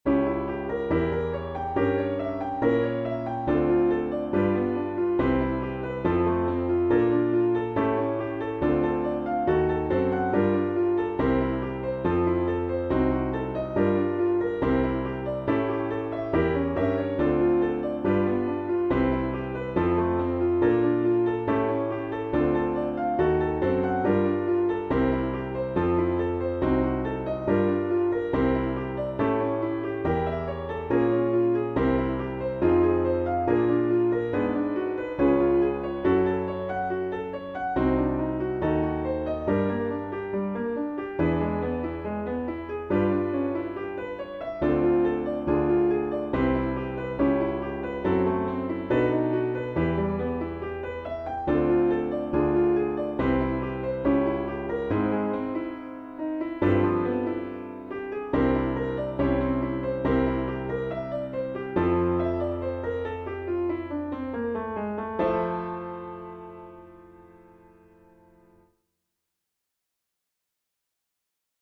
This represents literally decades of guitar foolery.